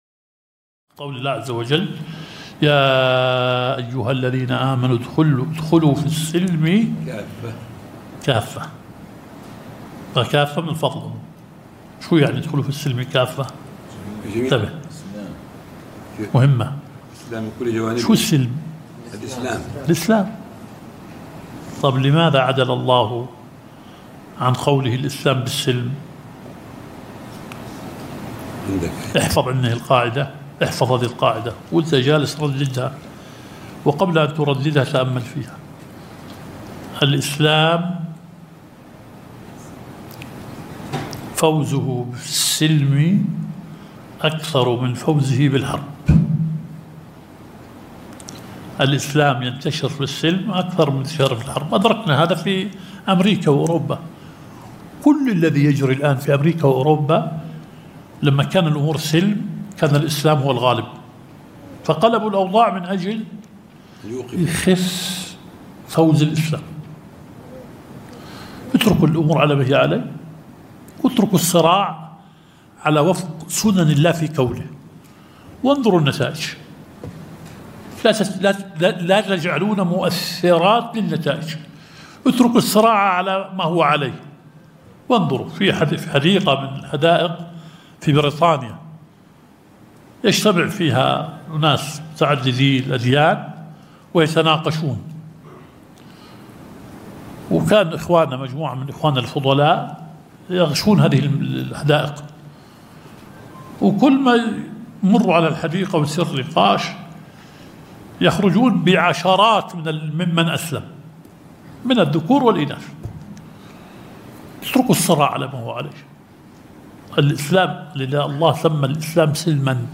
الدرس الخامس – شرح مبحث العام والخاص في أصول الفقه.